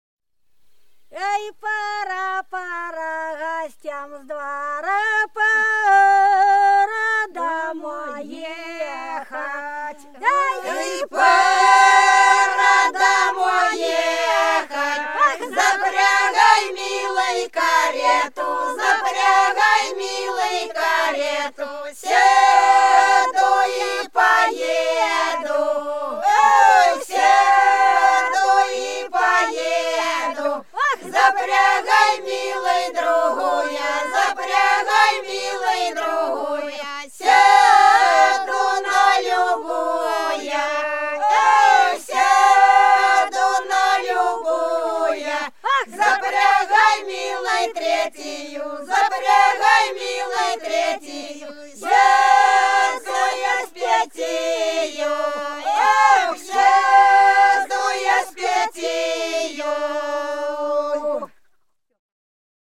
За речкою диво Пора, пора гостям с двора - плясовая (с. Пузево)
07_Пора,_пора_гостям_с_двора_-_плясовая.mp3